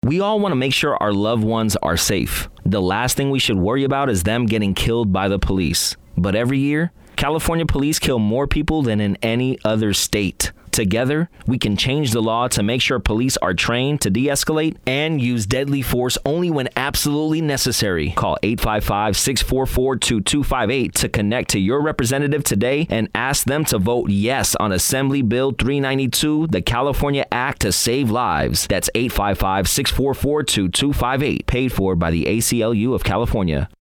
The organization is investing in 30 second radio spots that will be featured for a two-week period on KHYL-FM in Sacramento and KPWR-FM in